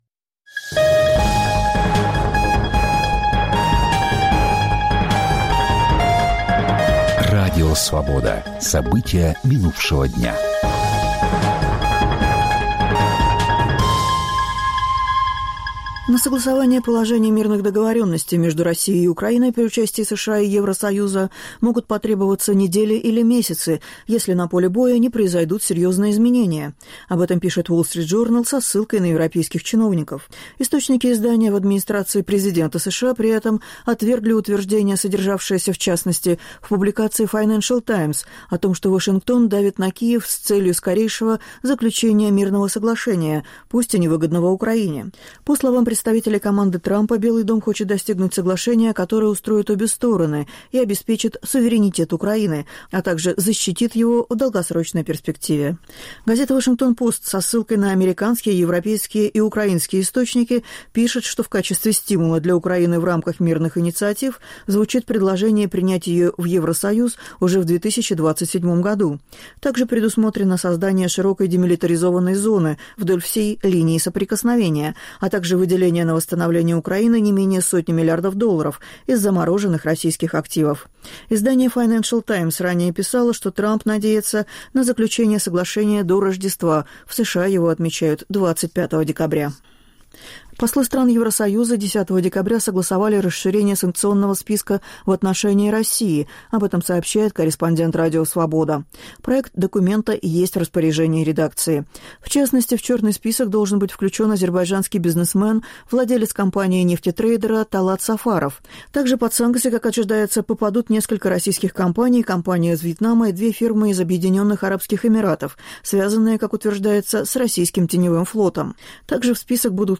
Аудионовости
Новости Радио Свобода: итоговый выпуск